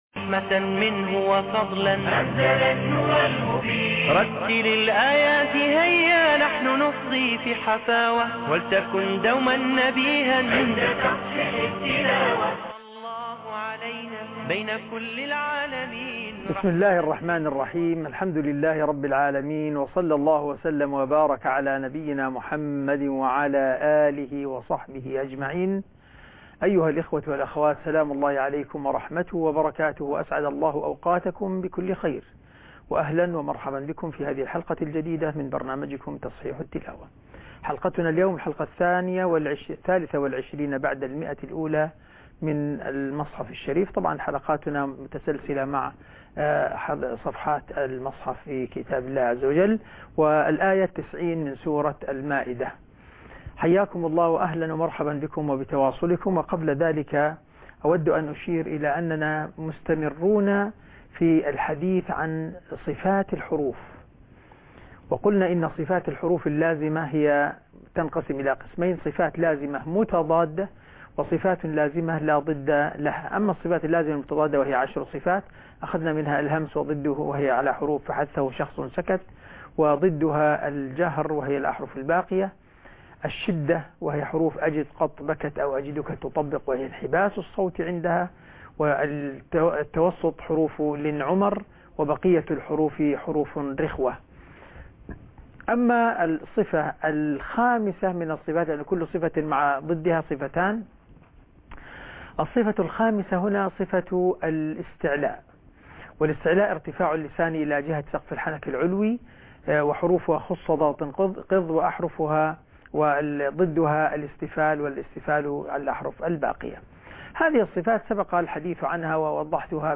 تصحيح تلاوة سورة المائدة من الاية90